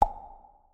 SFX_Dialog_02.wav